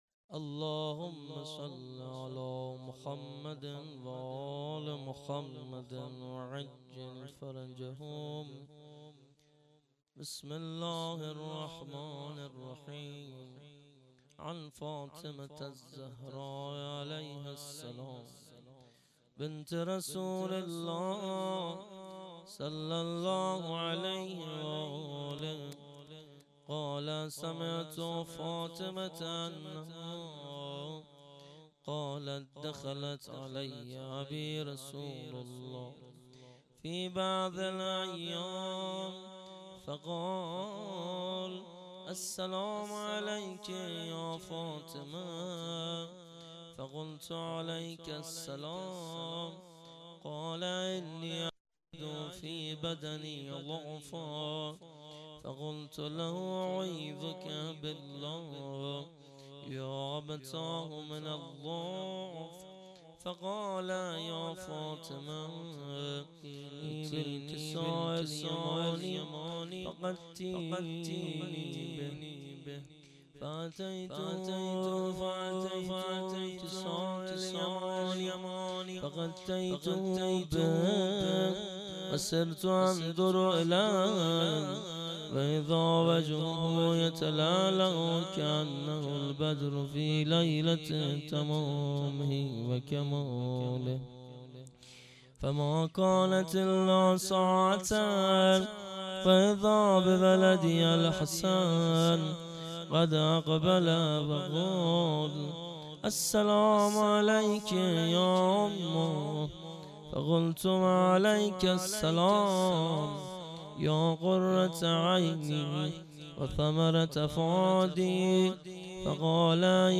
فاطمیه97- مجمع دلسوختگان بقیع- شب پنجم- قرائت حدیث شریف کساء